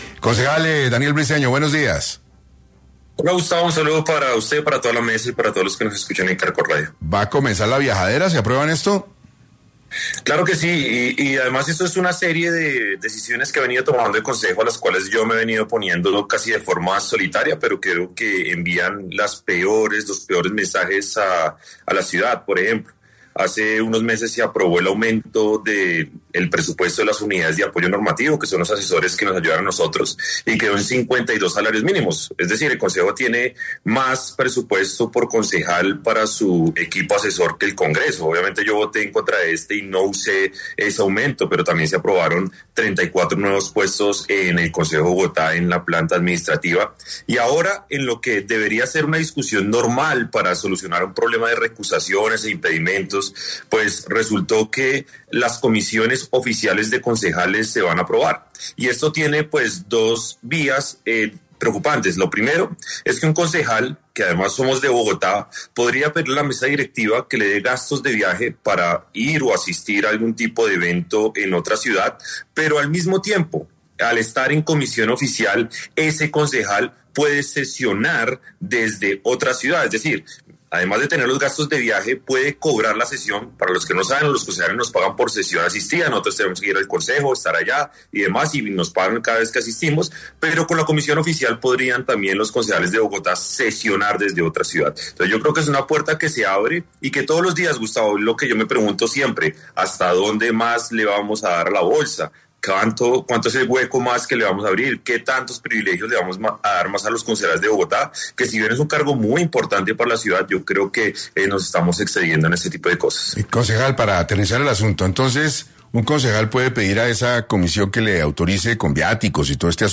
El concejal Daniel Briceño habló en 6AM de la nueva figura de comisiones que se aprobaría en el concejo lo que permitiría pagarle a los concejales que trabajen fuera de Bogotá